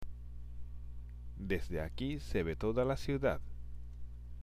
（デスデ　アキー　セベ　トーダ　ラ　シウダッ）